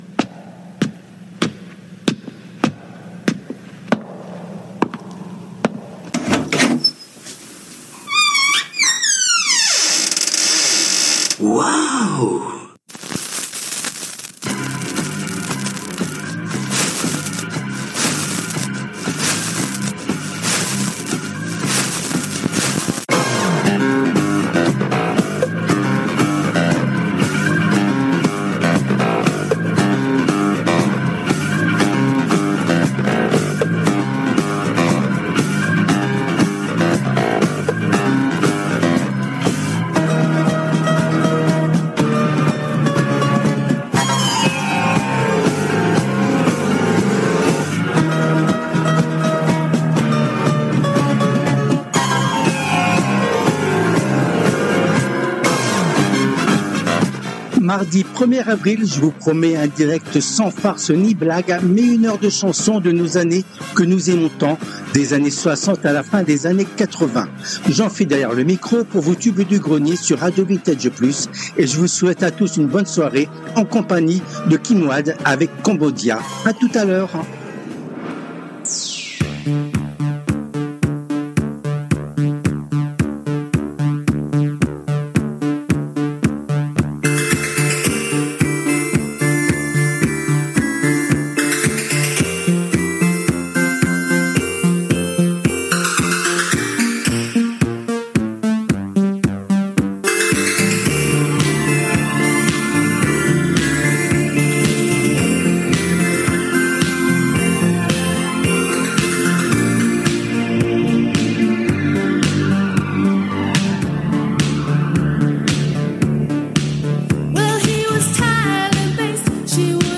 Les TUBES DU GRENIER constituent la première émission hebdomadaire phare de la semaine sur RADIO VINTAGE PLUS et cette édition a été diffusée en direct le mardi 01 avril 2025 à 19h depuis les studios de RADIO RV+ à PARIS .
Les Tubes connus ou oubliés des 60's, 70's et 80's